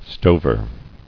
[sto·ver]